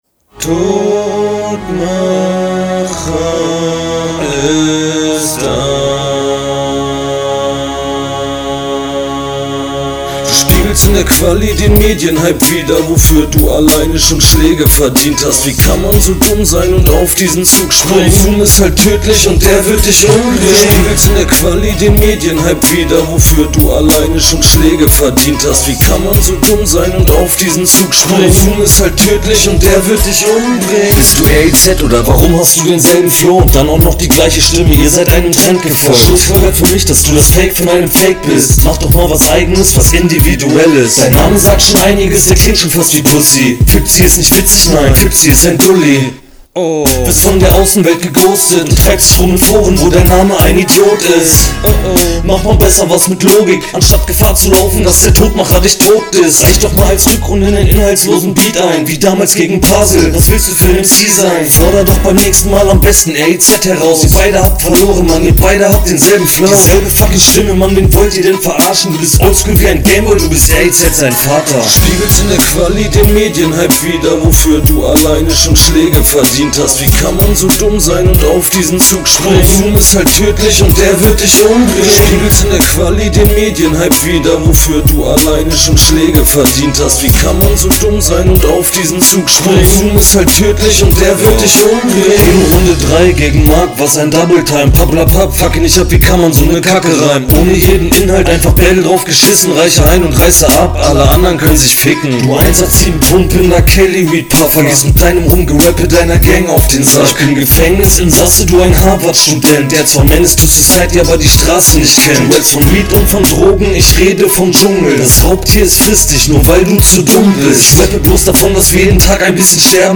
Wieder audio quali nicht so geil.
Flow: Kommst auf deinem eigenem Beat tatsächlich mal nicht so ungeil wie sonst.